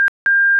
При разряженной батарее сигнал бипера двойной, первый длительностью 0.08cек, второй длительностью 0.35cек. тон 1600Гц.